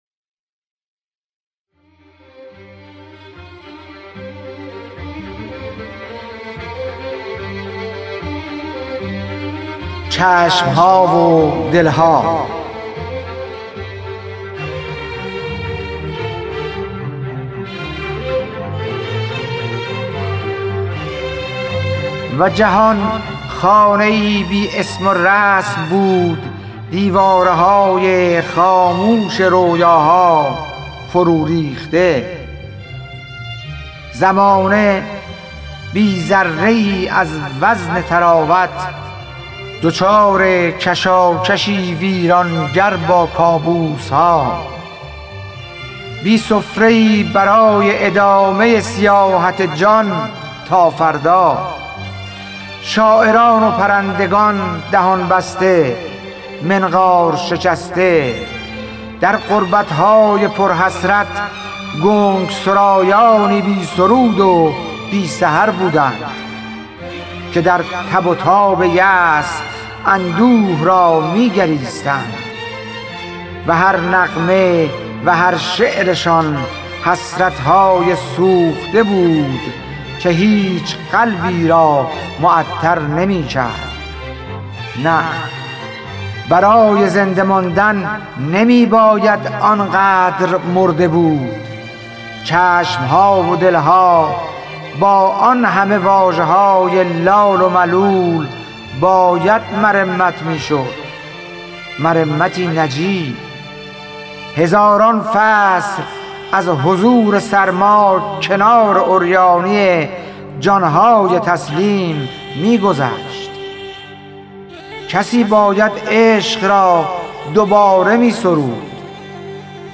خوانش شعر سپید عاشورایی / ۳